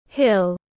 Quả đồi tiếng anh gọi là hill, phiên âm tiếng anh đọc là /hɪl/.
Hill /hɪl/